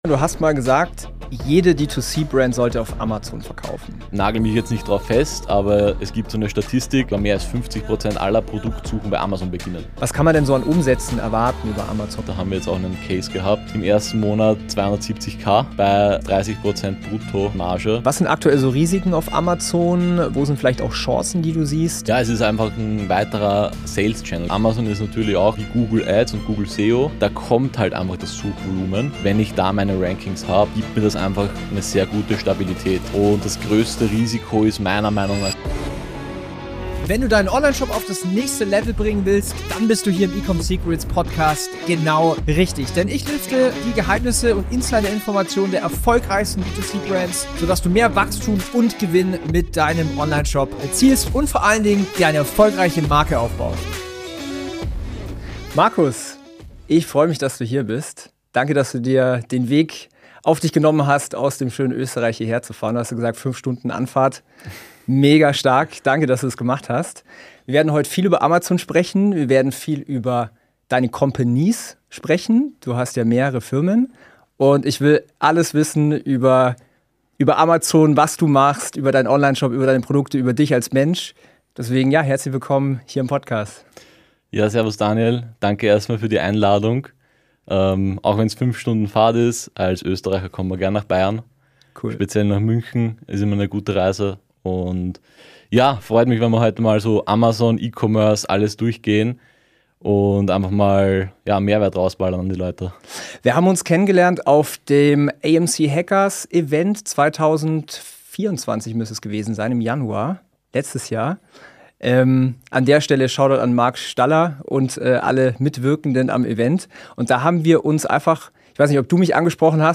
Sollte jede Ecom Brand auf Amazon verkaufen? Interview